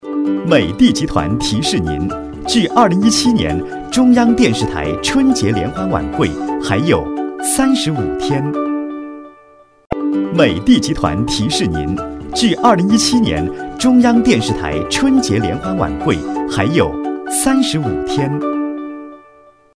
• 男S39 国语 男声 广告-美的集团春晚倒计时提示 激情激昂|沉稳|积极向上